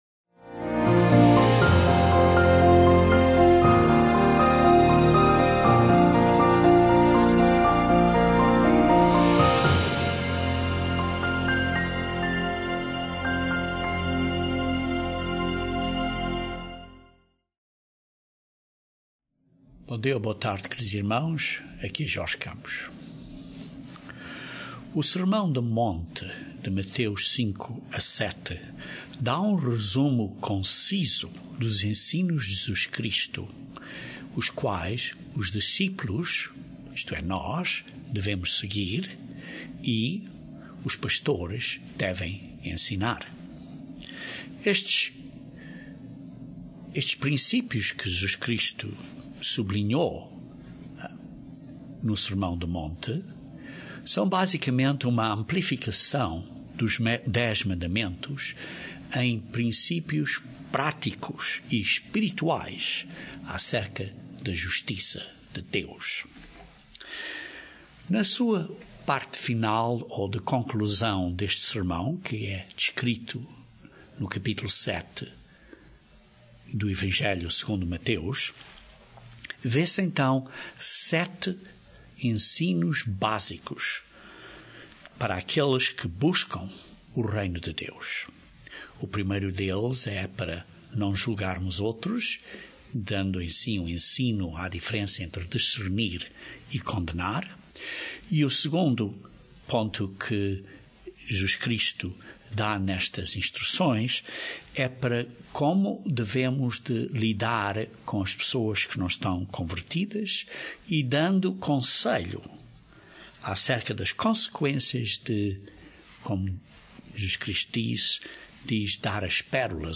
Este sermão continua o estudo Bíblico de Mateus, cobrindo quatro princípios importantes que Jesus ensinou no sermão do monte.